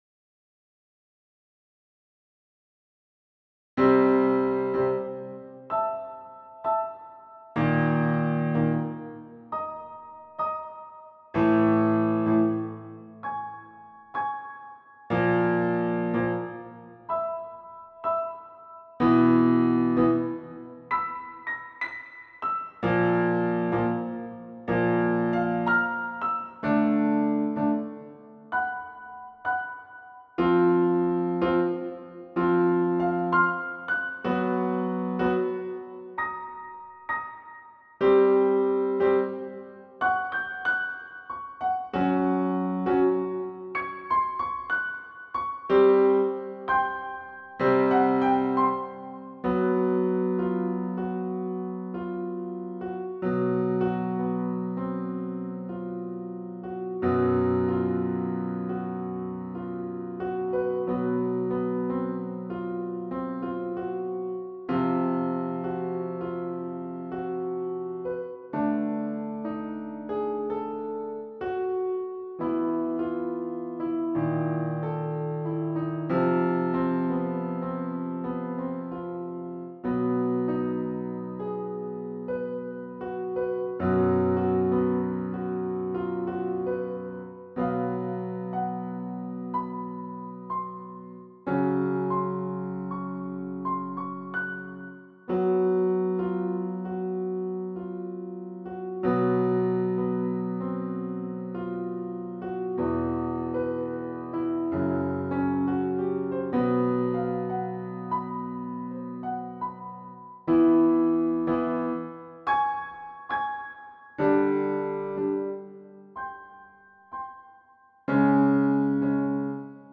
Die Rhythmik ist locker und spielerisch.
Melodiestimme in langsamem Übungs-Tempo